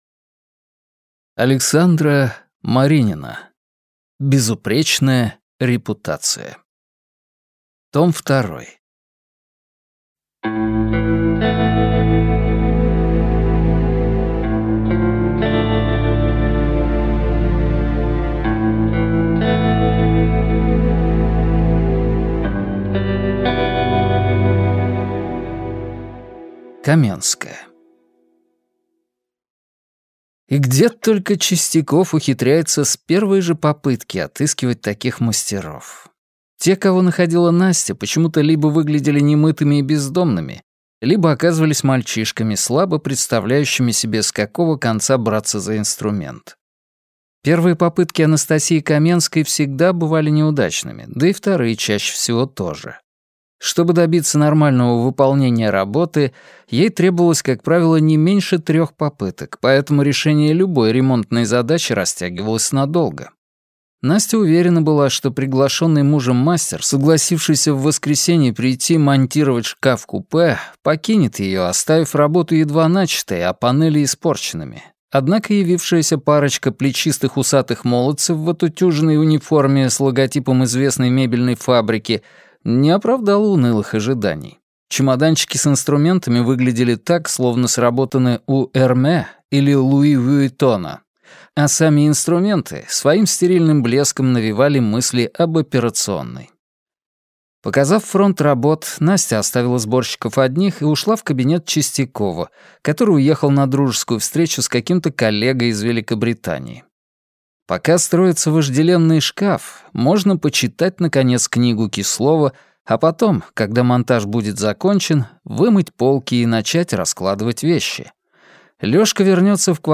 Аудиокнига Безупречная репутация. Том 2 - купить, скачать и слушать онлайн | КнигоПоиск